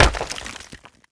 stone_break.ogg